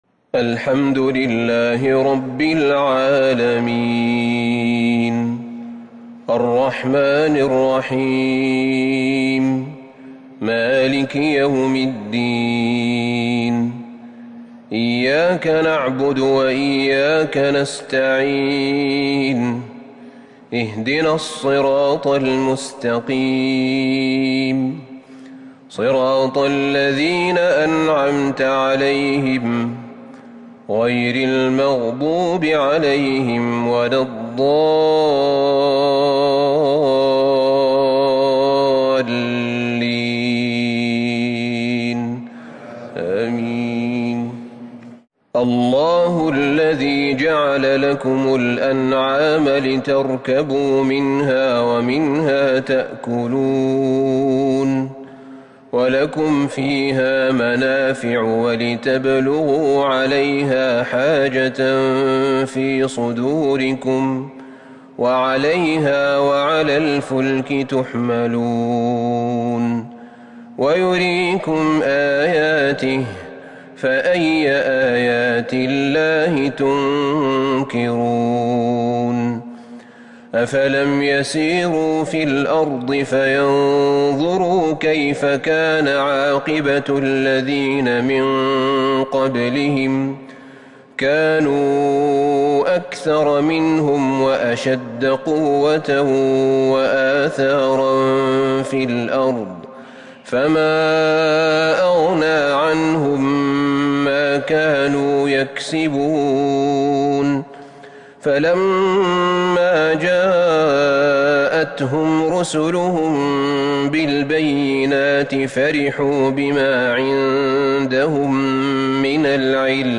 صلاة العشاء 1 ربيع الثاني 1442 هـ خواتيم سورتي غافر وفصلت Isha prayer from Surah Ghafir. and Fussilat / 16/11/2020 > 1442 🕌 > الفروض - تلاوات الحرمين